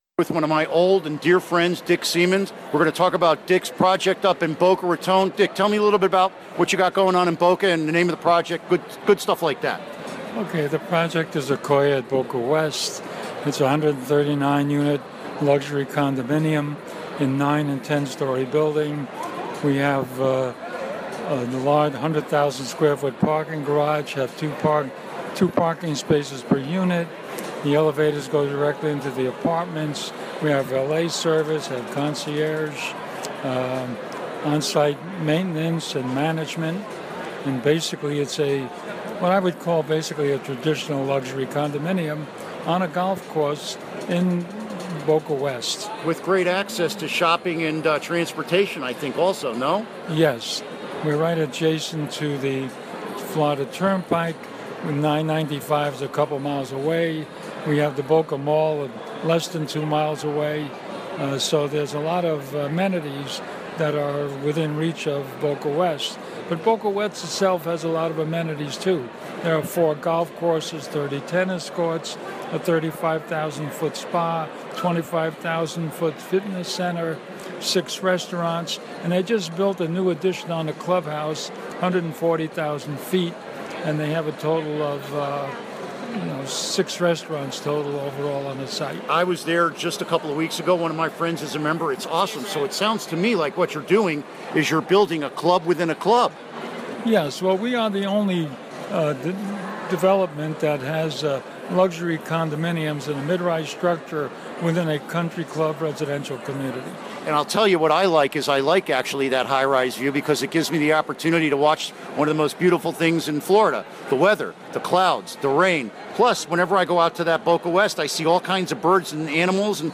Interview Segment Download Now!